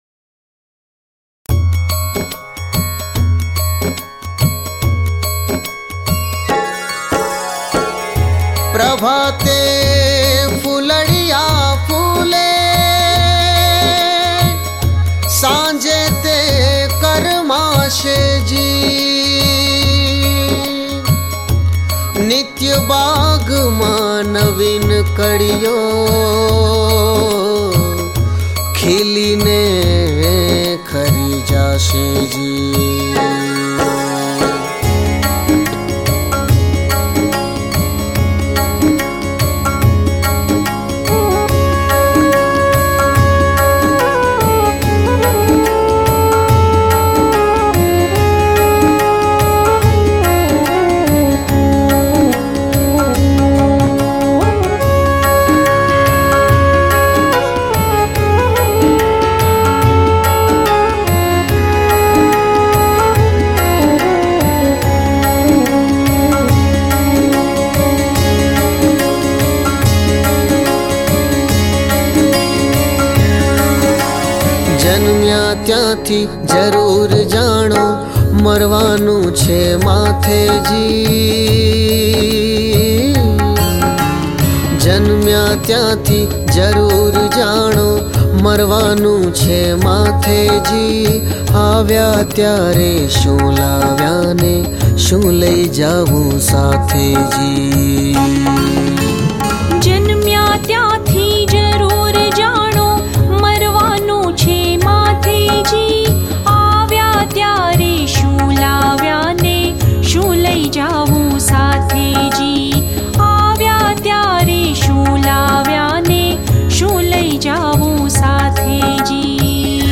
🎵 Janmyā Tyāñthī / જન્મ્યા ત્યાંથી – રાગ : ધોળ પદ – ૧